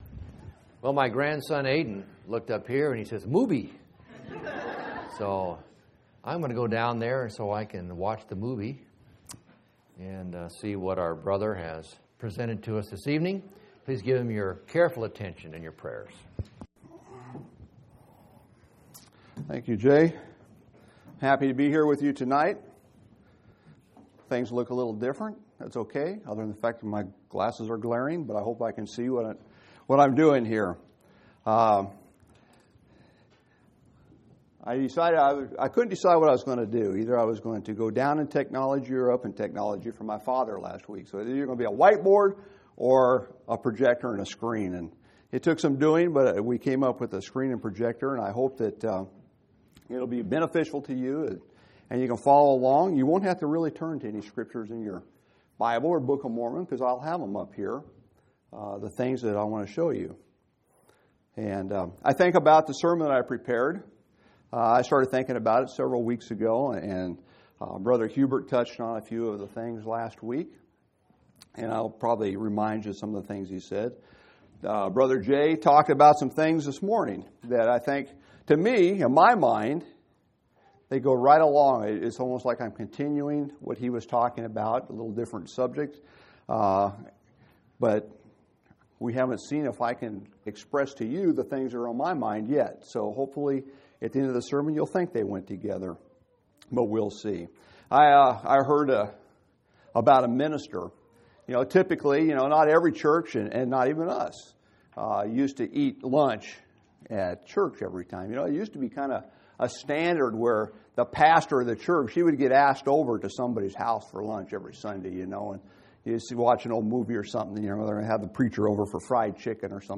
1/22/2006 Location: Phoenix Local Event